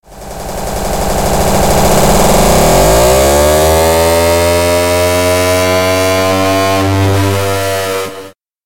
hard-alarm_25499.mp3